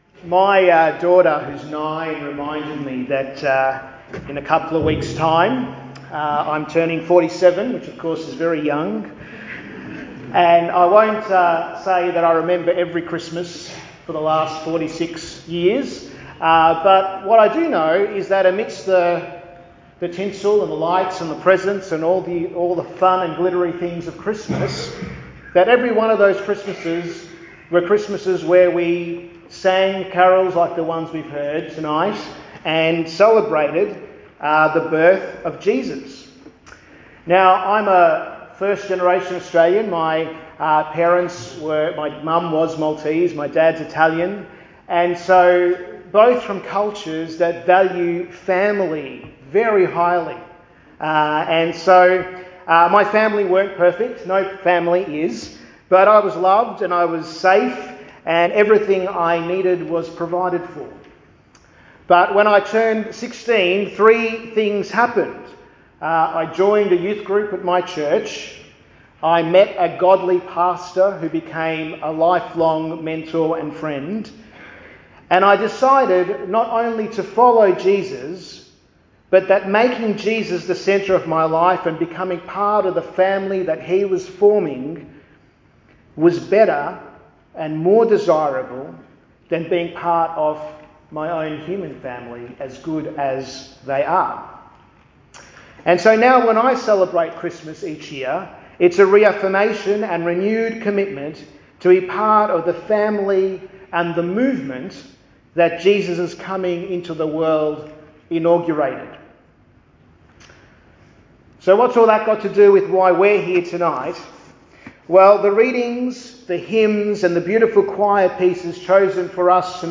Sermons | St Hilary's Anglican Church